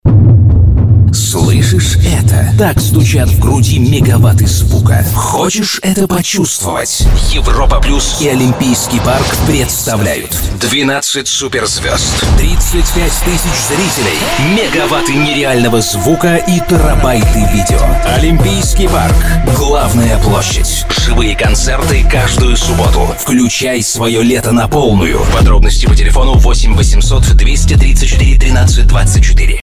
еще один промо ролик для оч. известной радиостанции..